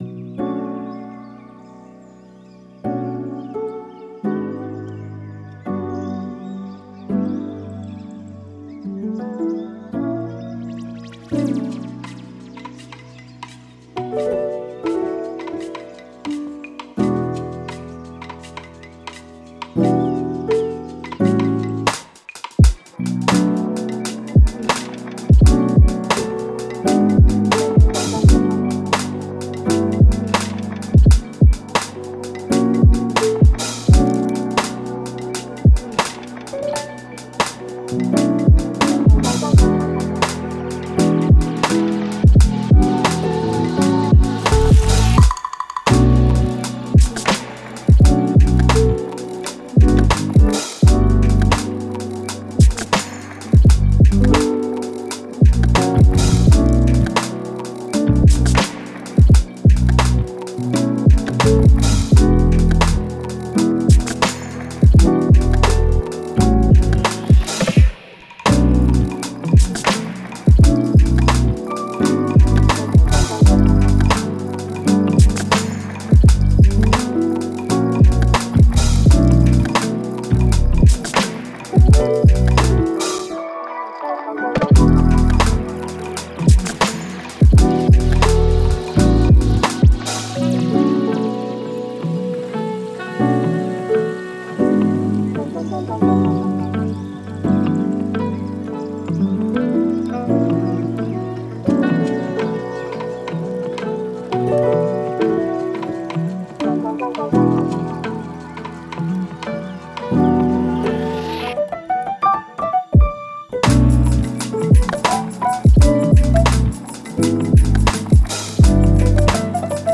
Genre: Lofi Hip Hop.